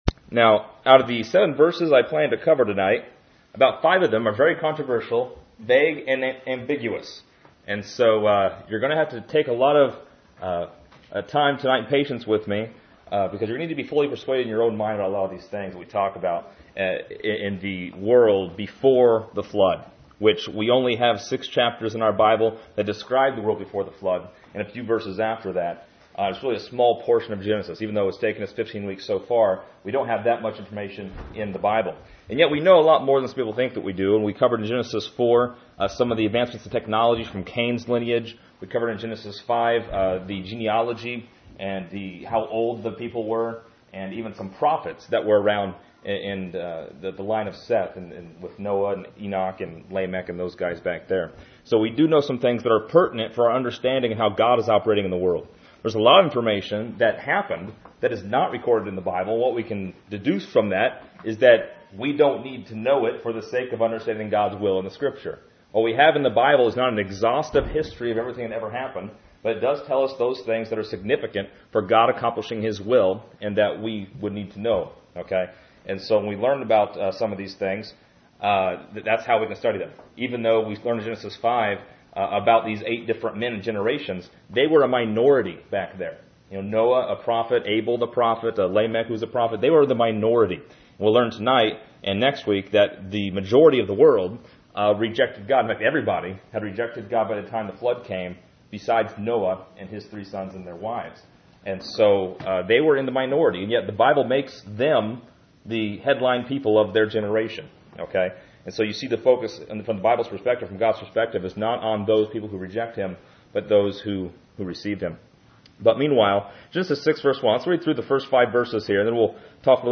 This lesson is part 15 in a verse by verse study through Genesis titled: Before the Flood.